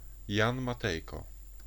Pronunciation of "Jan Matejko".
Pl-Jan_Matejko.ogg.mp3